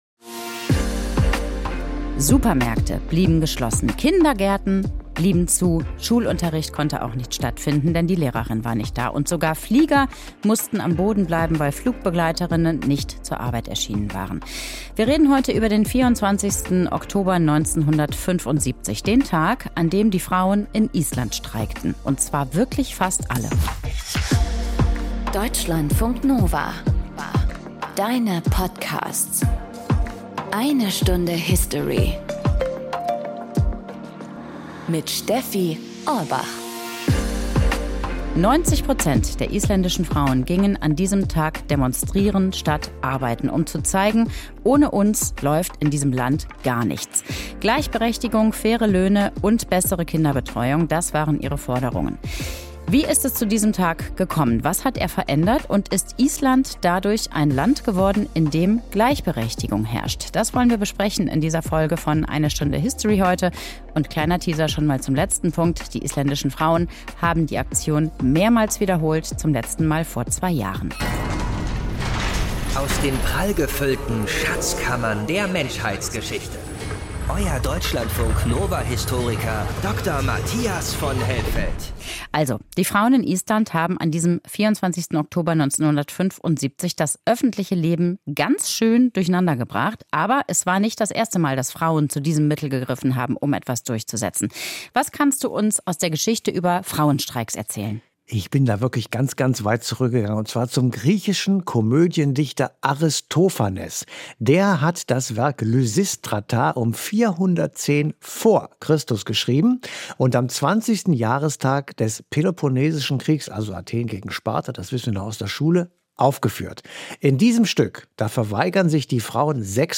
isländische Unternehmerin
Historikerin